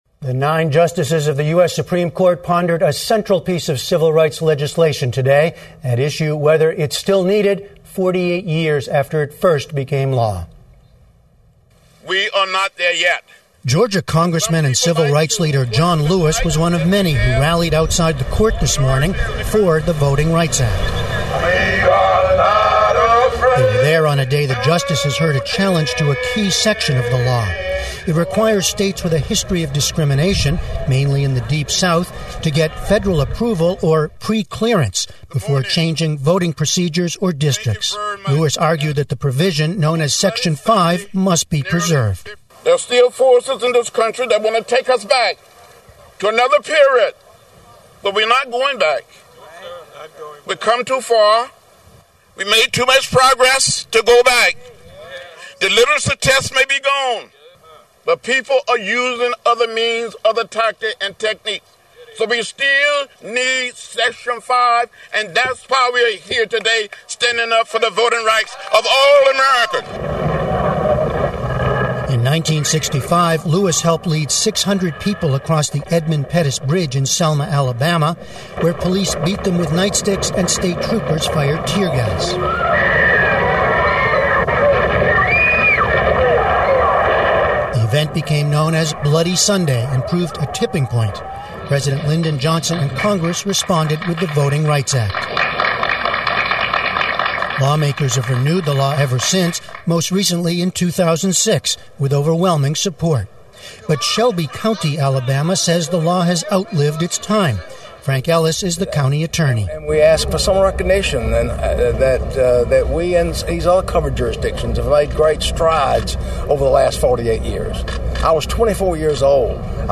News
英语访谈节目:最高法院召开听证会反对《选举权法》的关键条款